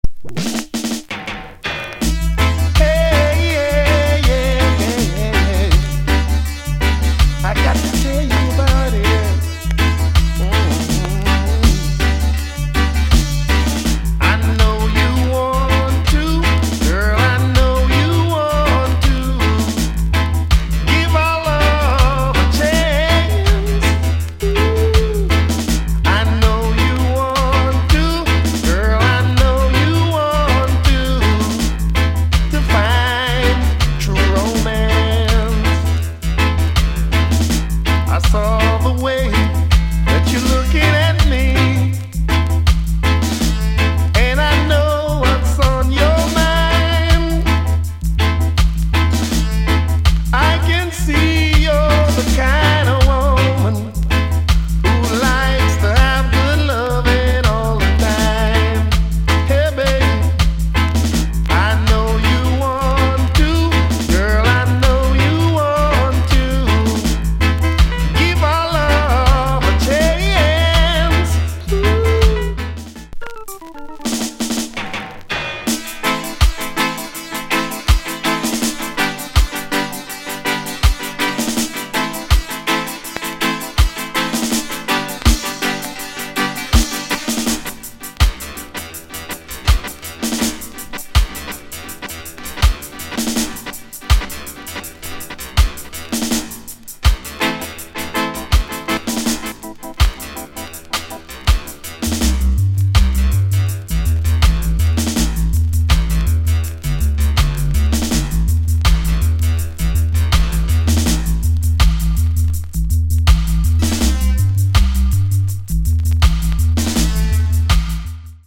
Good Vocal Tune